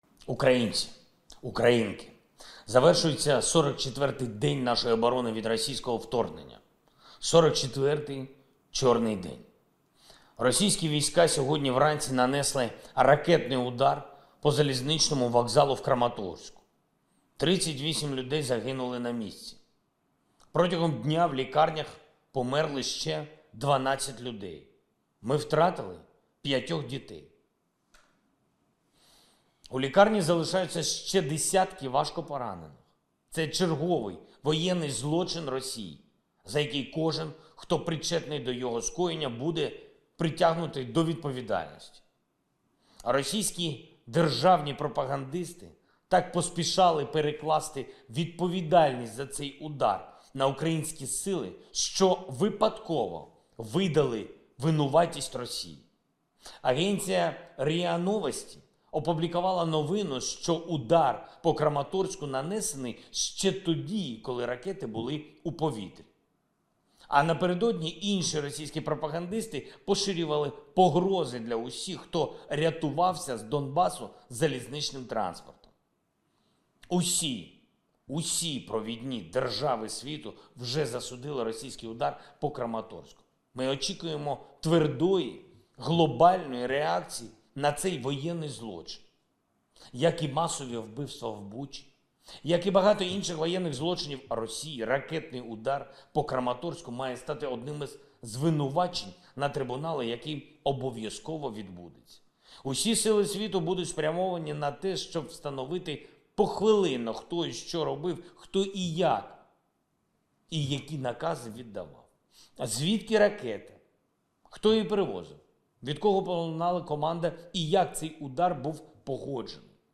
44 день війни. Звернення Президента України
Володимир Зеленський звернувся до нації у 44 день війни в Україні.